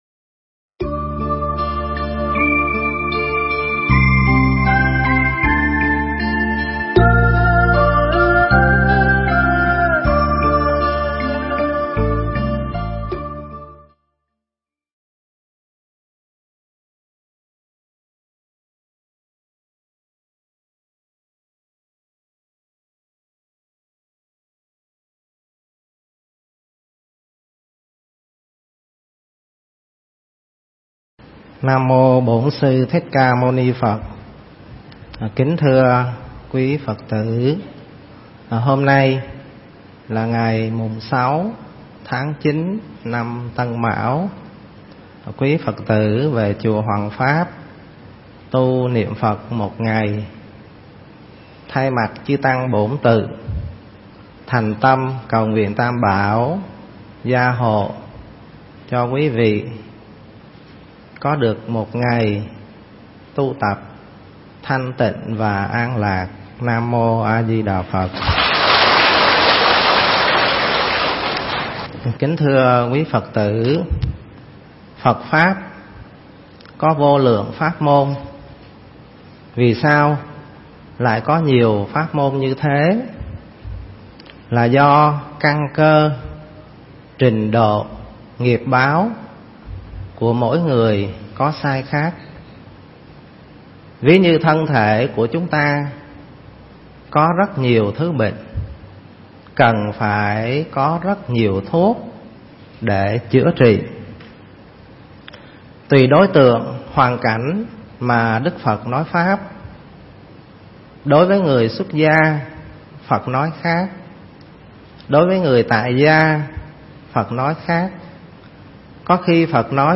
Nghe Mp3 thuyết pháp Đừng Để Tâm Nhỏ Hẹp